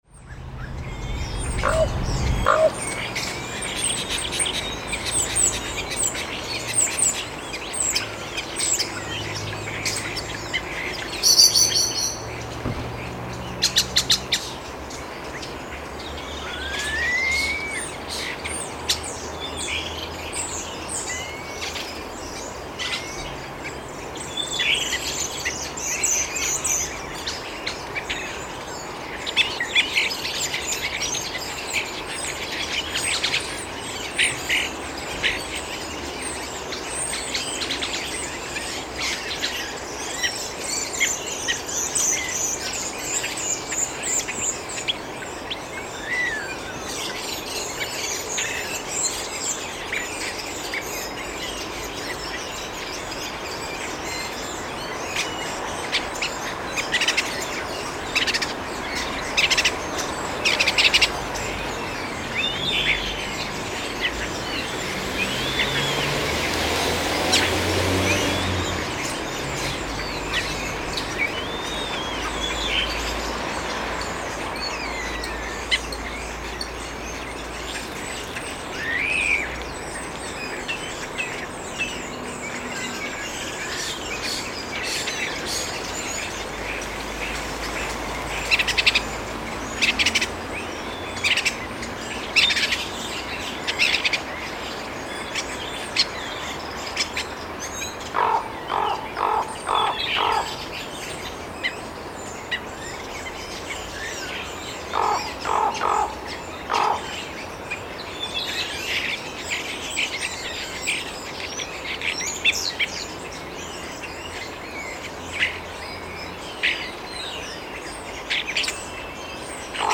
Fuglasöngur 13. mars 2010
Mér tókst þó á laugardegi að skjótast út með tækin og taka upp lítilsháttar fuglaþing.
Í fjarska, í u.þ.b. 200-300 metra fjarlægð var einmana hrafn hugsanlega að biðla til maka.
Í upptökunni má heyra hvernig fuglasöngurinn fjarar út en í staðinn má heyra vængjaþyt. Fyrir utan stanslausan bílanið má heyra í bjöllu kattar, strengjaslátt fánastangar, börn á hlaupum á milli húsa og hurðaskelli svo fátt eitt sé nefnt. Tekið var upp í 24bit/44Khz á Olympus LS10 með Telinga parabólu .
Notast þurfti við lágtíðnisíu á 82Hz til að lækka í þrúgandi bílaumferð og lítilsháttar vindkviðum sem komu af og til.